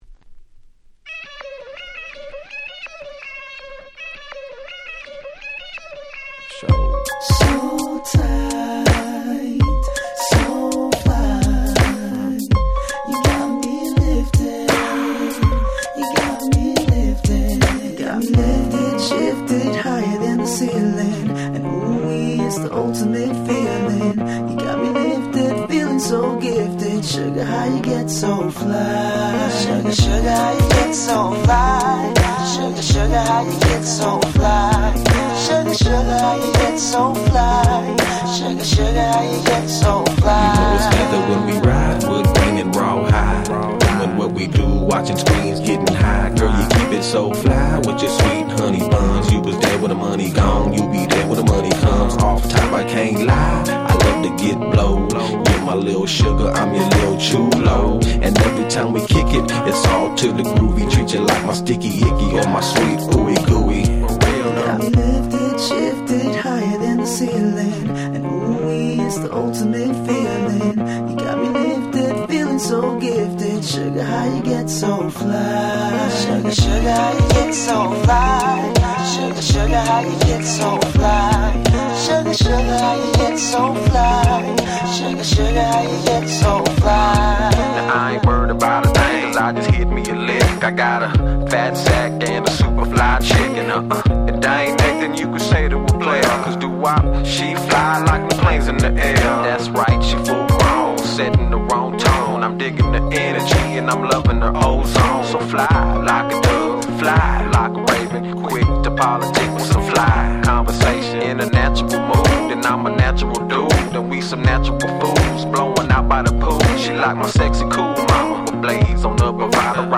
03' Smash Hit West Coast Hip Hop !!